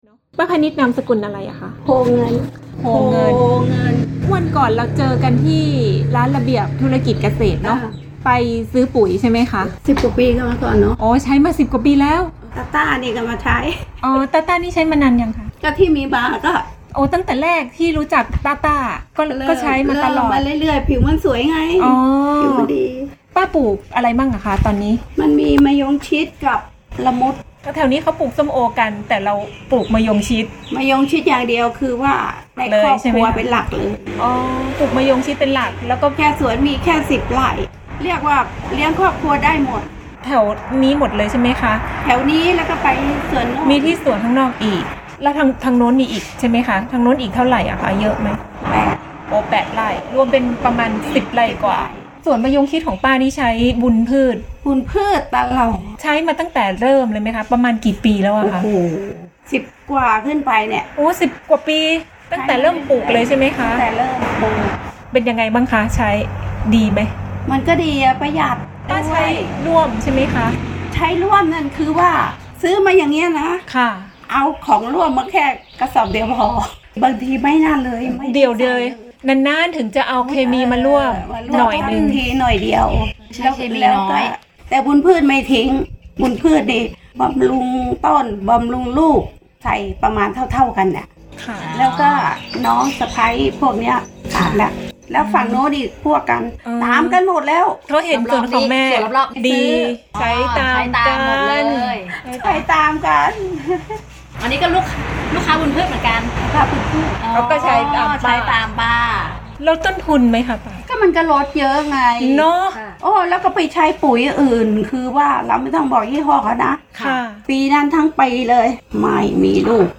เสียงสัมภาษณ์เกษตรกร